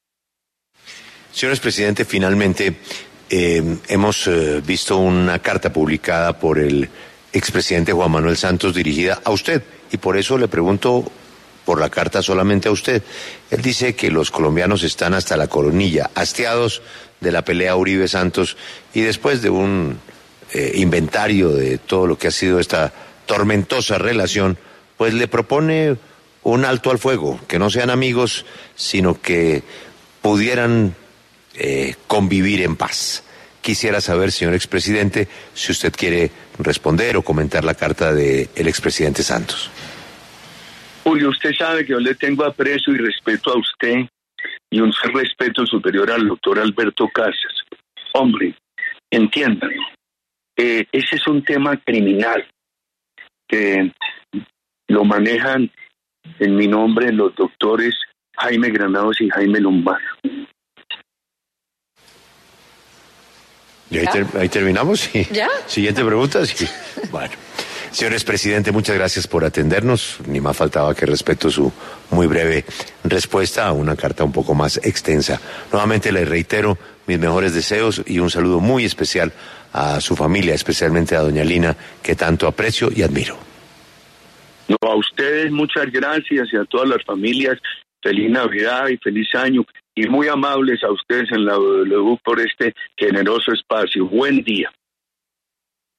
En diálogo con Julio Sánchez Cristo para La W, el expresidente Álvaro Uribe se pronunció sobre la respuesta de Juan Manuel Santos.